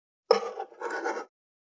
574肉切りナイフ,まな板の上,包丁,ナイフ,
効果音厨房/台所/レストラン/kitchen食器食材
効果音